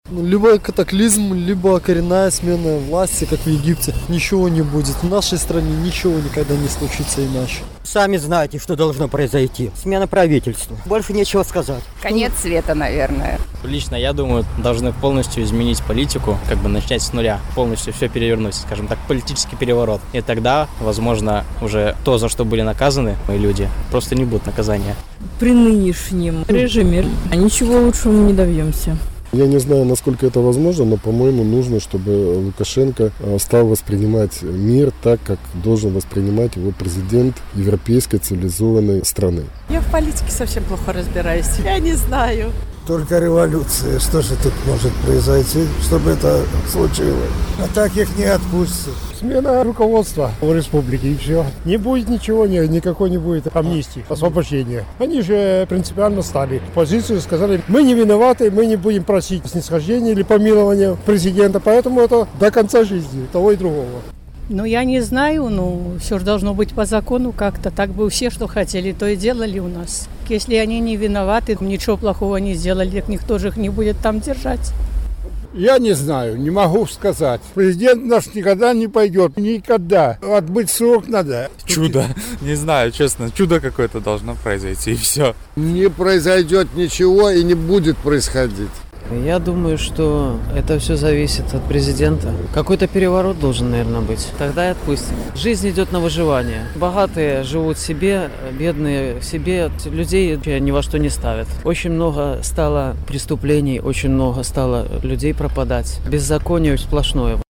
Апытаньне ў Гомелі: Што павінна адбыцца, каб Лукашэнка вызваліў палітвязьняў?
Гомель, 14 лютага 2012